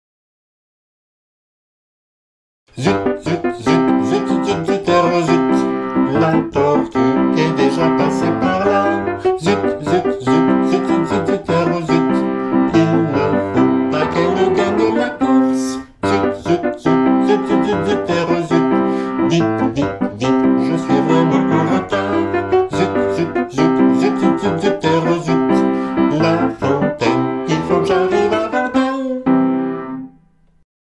un conte musical pour enfants